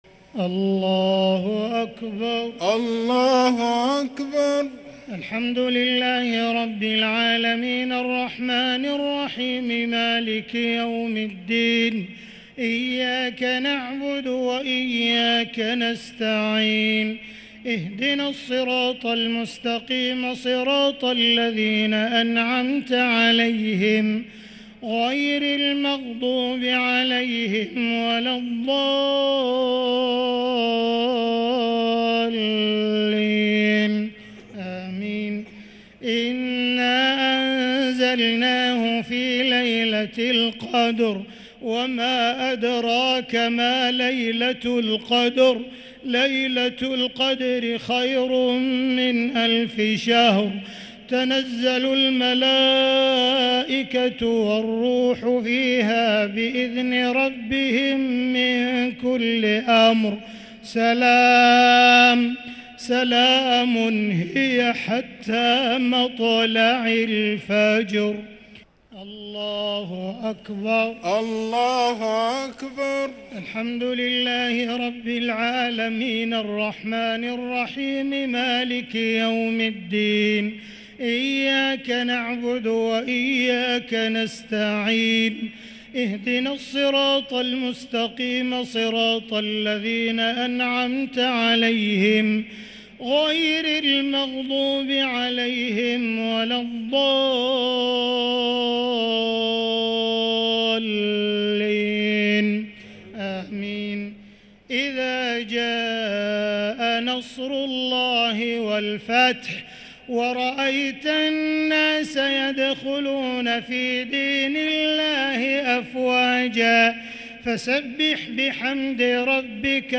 الشفع و الوتر ليلة 27 رمضان 1444هـ | Witr 27 st night Ramadan 1444H > تراويح الحرم المكي عام 1444 🕋 > التراويح - تلاوات الحرمين